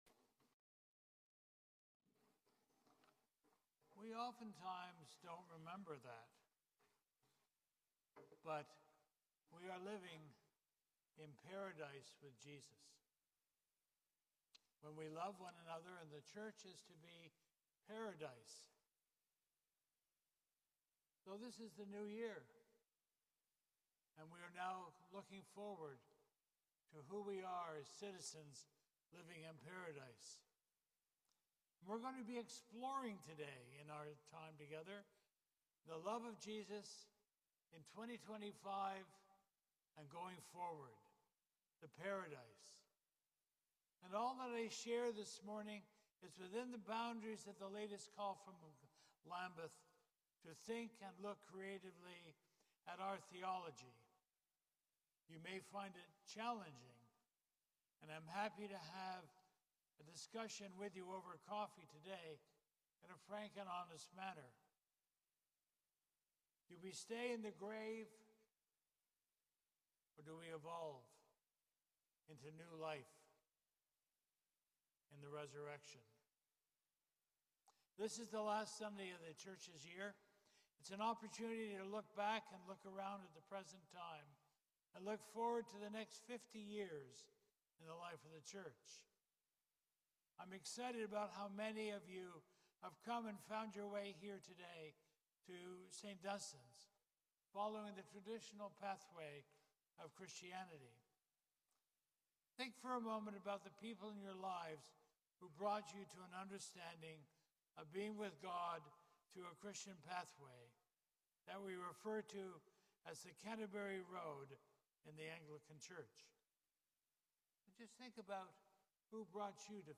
Sermon on the Reign of Christ Sunday